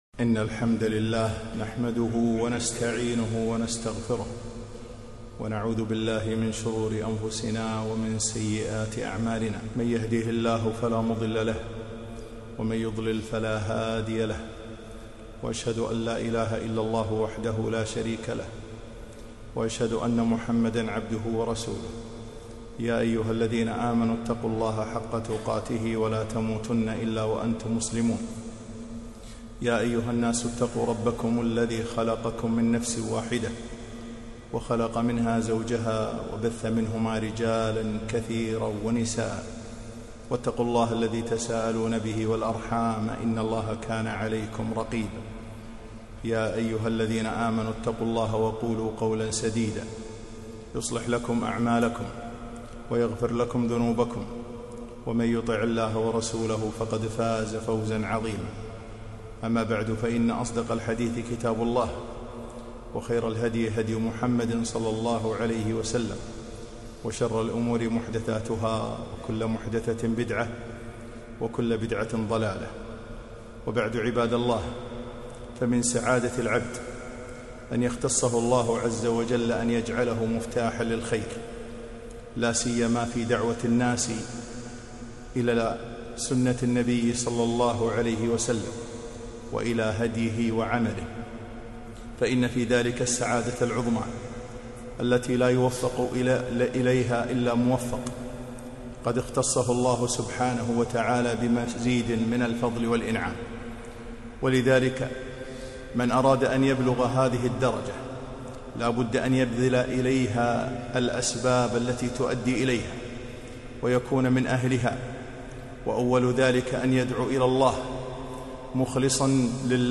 خطبة - ذم الكبر وأهله